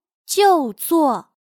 就座/jiù zuò/Toma asiento